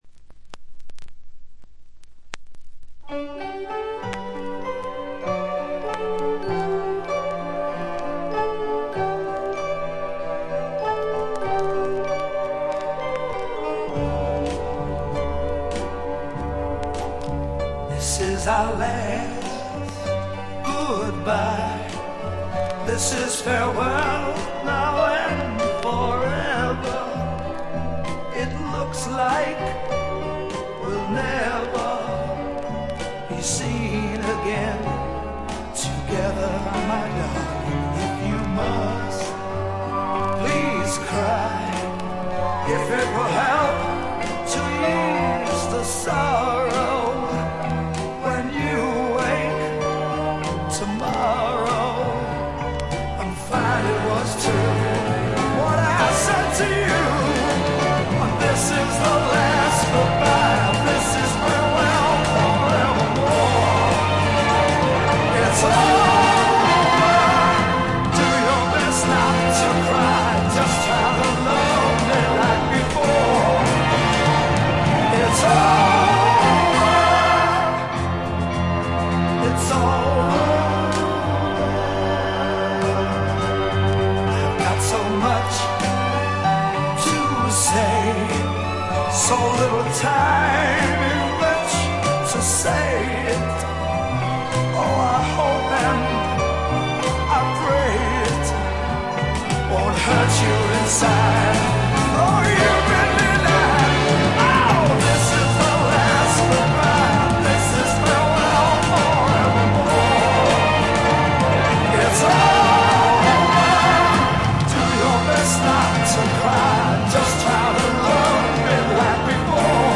ところどころでチリプチ、バックグラウンドノイズ。特に気になるような大きなノイズはありません。
試聴曲は現品からの取り込み音源です。